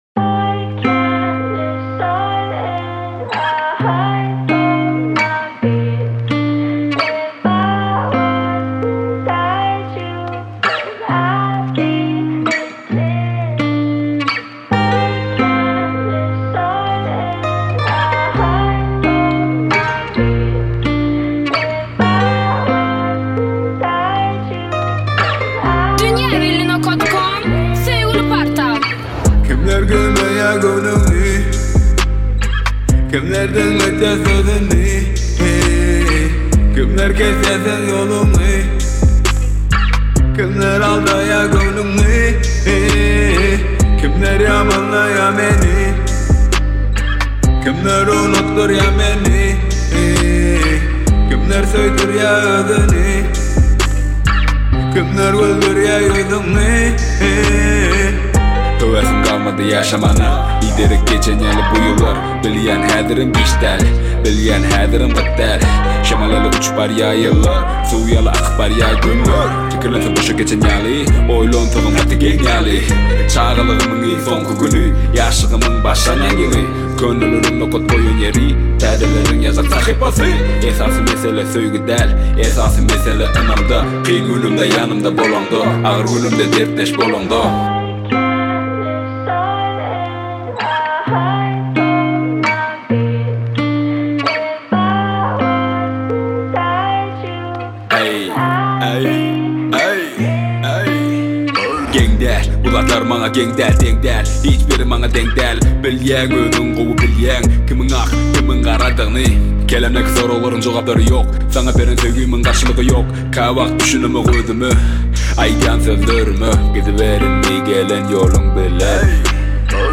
Bölüm: Türkmen Aýdymlar / Rep